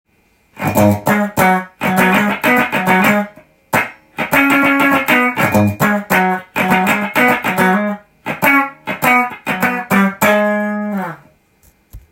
ハムバッカーでカッティング
ハムバッカーは、存在感抜群の低音が出ています。